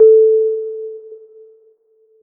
ping.ogg